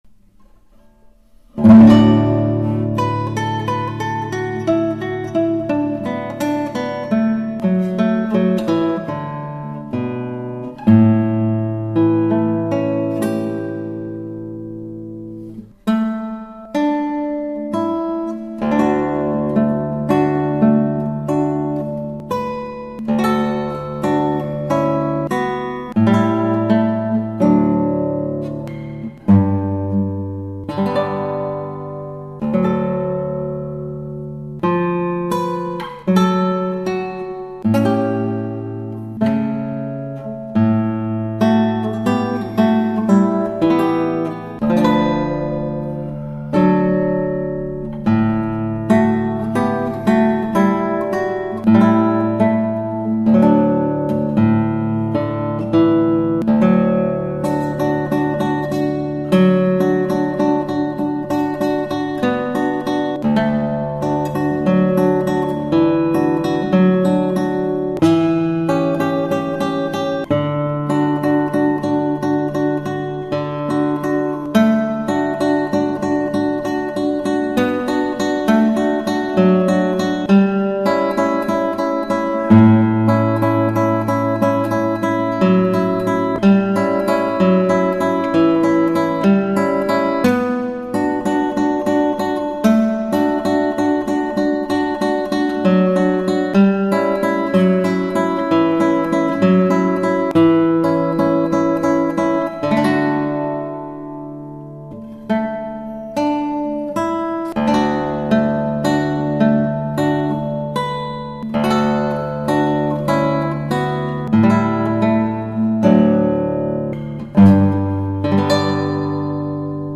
ギターは尾野ギター